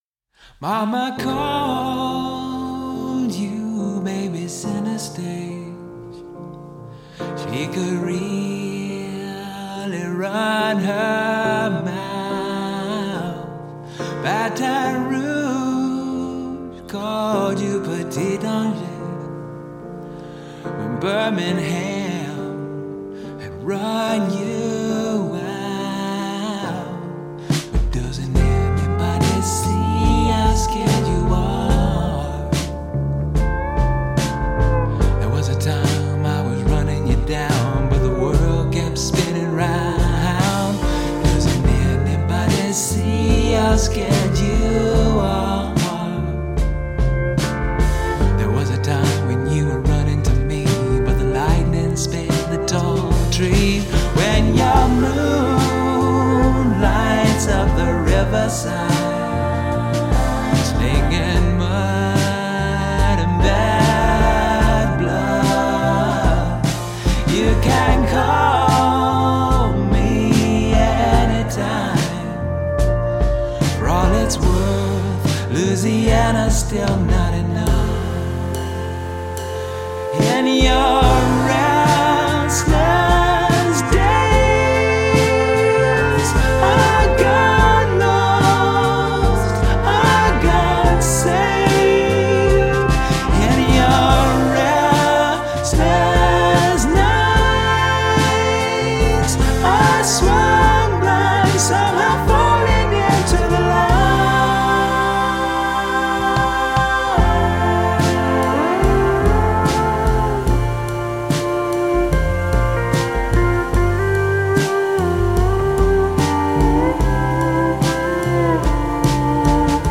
is like the musical equivalent of a gorgeous summer sunset.